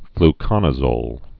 (fl-kŏnə-zōl, -kōnə-)